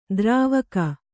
♪ drāvaka